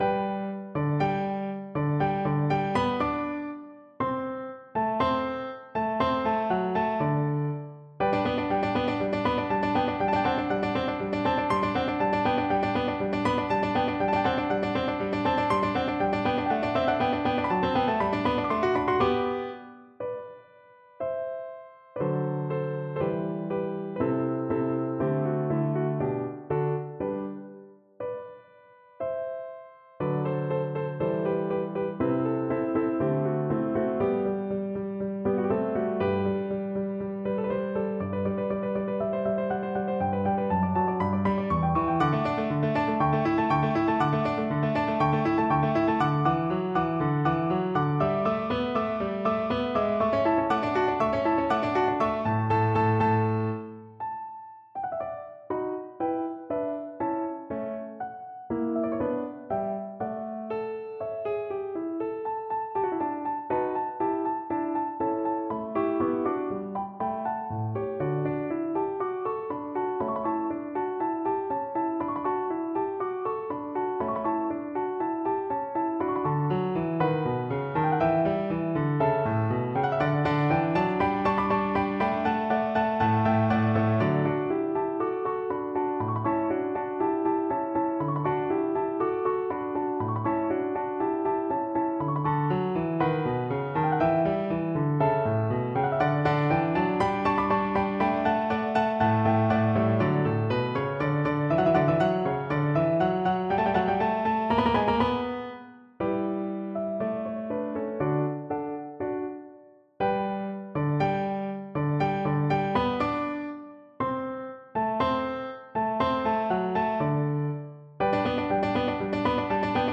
Classical Mozart, Wolfgang Amadeus Eine Kleine Nachtmusik (1st movement complete) Piano version
G major (Sounding Pitch) (View more G major Music for Piano )
Allegro (View more music marked Allegro)
4/4 (View more 4/4 Music)
Piano  (View more Advanced Piano Music)
Classical (View more Classical Piano Music)